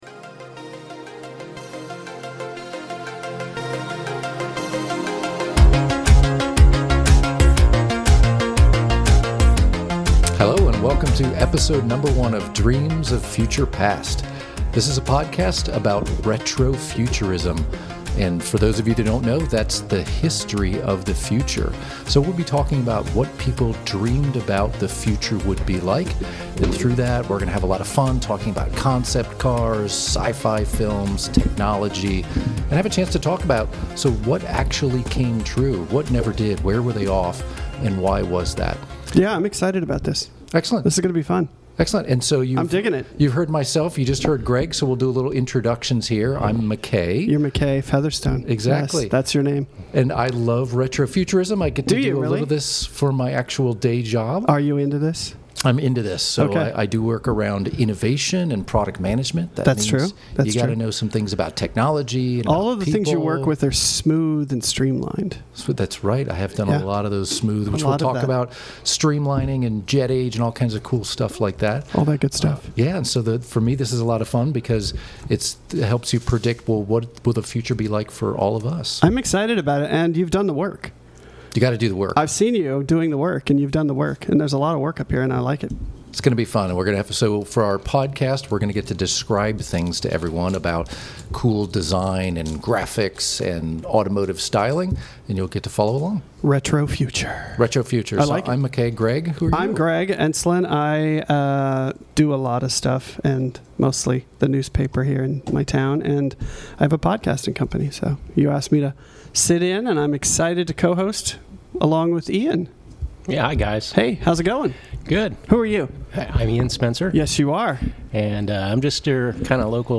With an upbeat, conversational tone, the show will dive into iconic retro-futurist moments — from concept cars and world’s fairs, to sci-fi films and technology — and ask: what came true, what never did, and why?